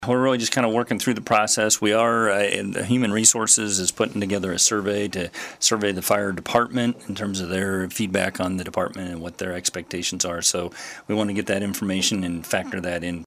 City Manager Mike Schrage joined in on the KSAL Morning News Extra to touch on a range of issues including the early stages of finding a new Fire Chief to lead the department.